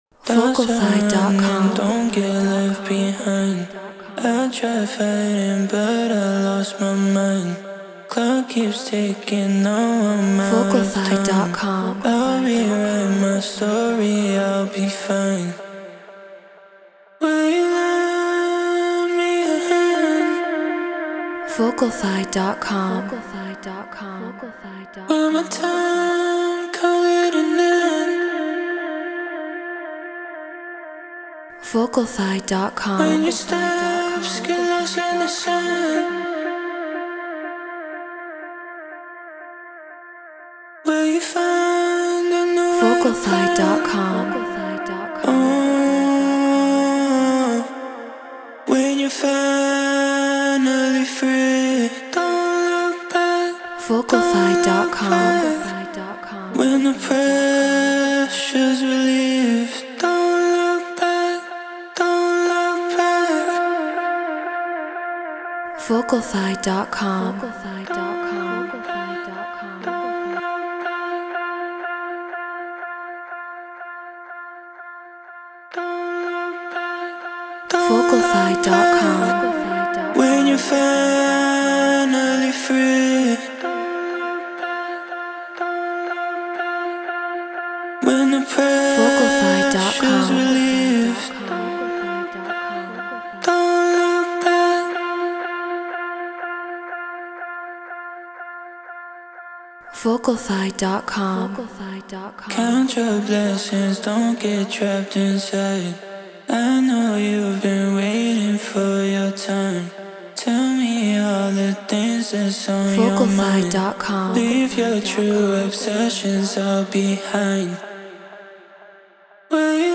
House 122 BPM Fmin
Human-Made